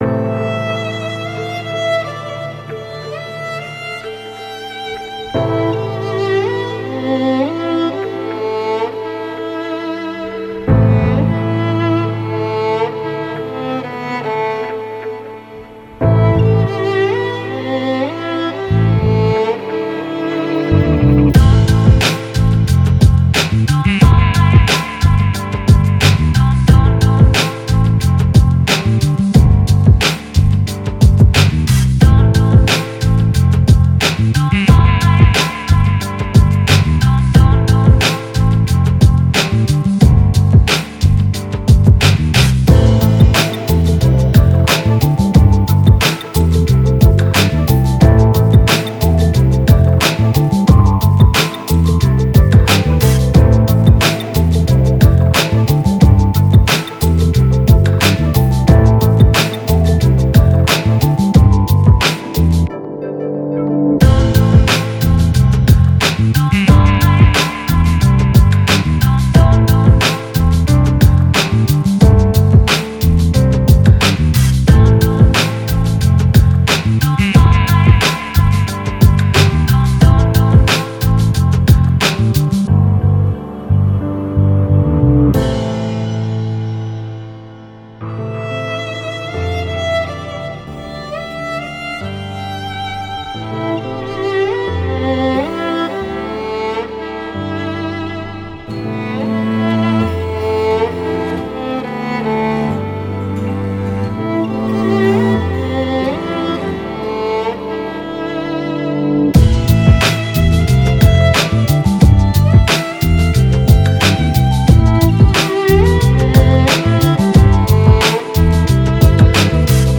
Жанр: Electronic / Downtempo / Lounge / Chillout